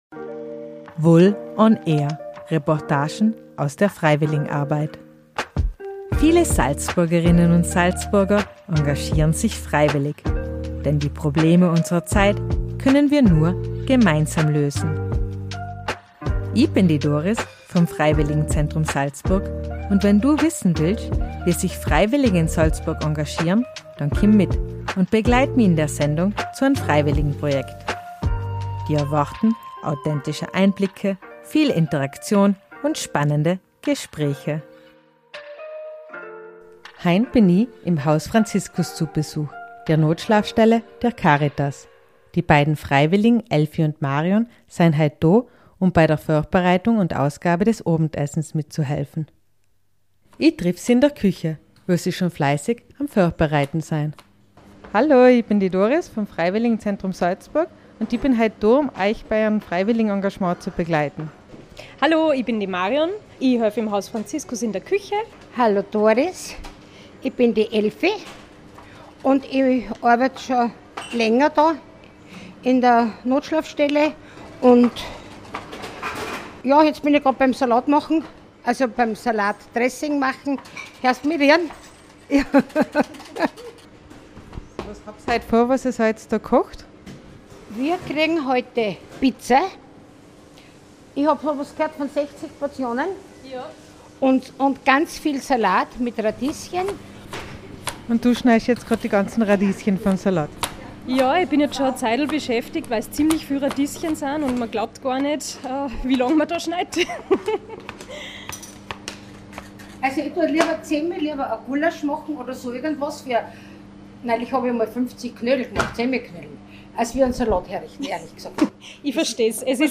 - Reportagen aus der Freiwilligenarbeit Podcast